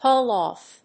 アクセントhául óff